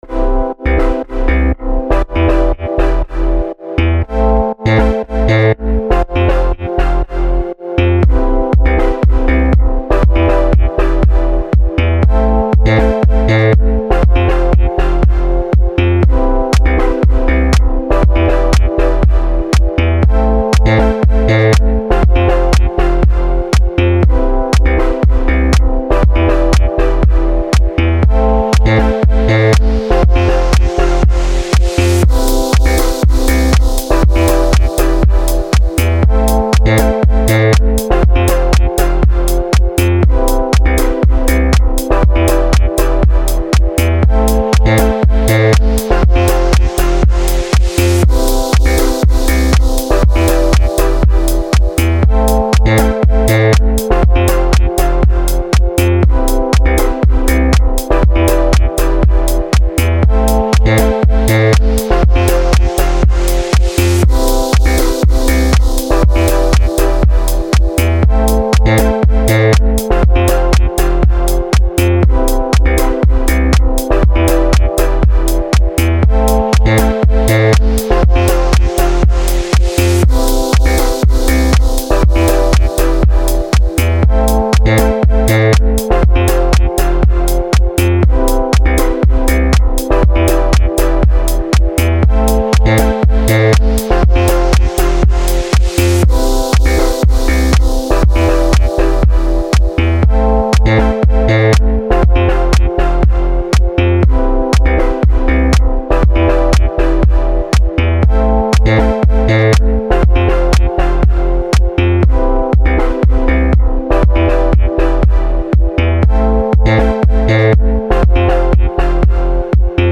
Musique_video.mp3